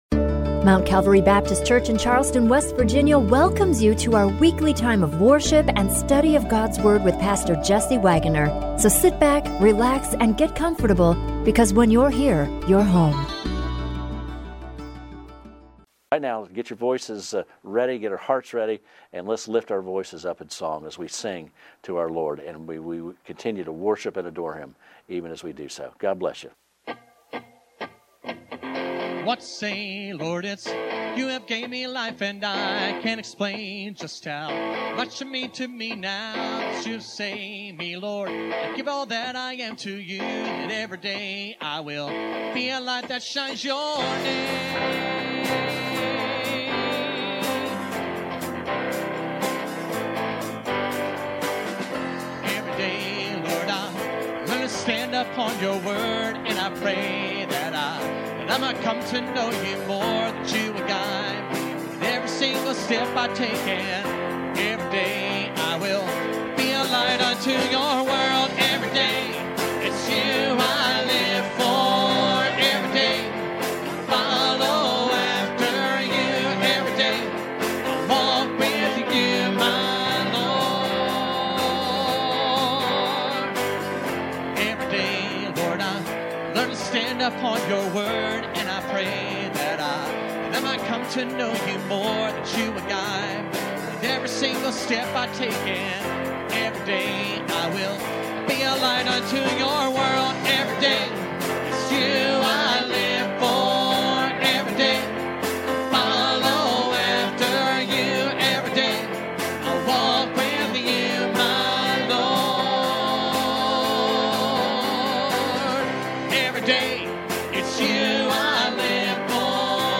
The Praise Team reminds to live in the moment with a great song by Matthew West.